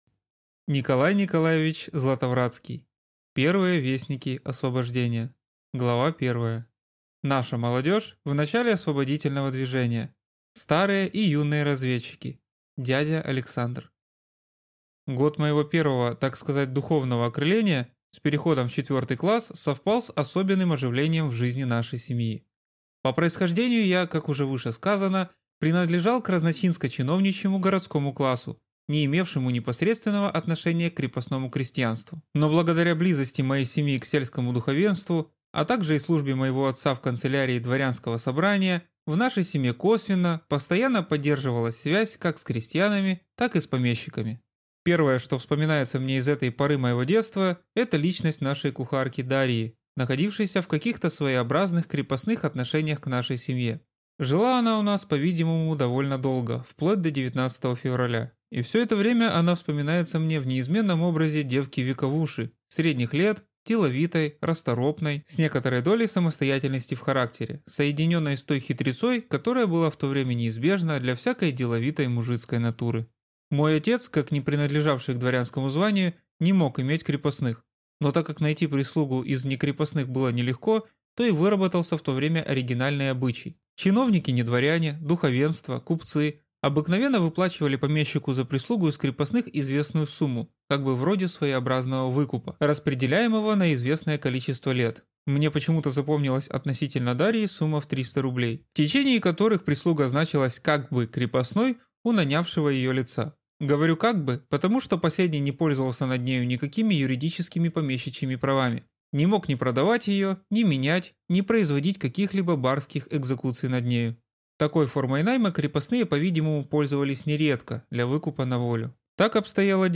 Аудиокнига Первые вестники освобождения | Библиотека аудиокниг